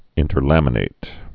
(ĭntər-lămə-nāt)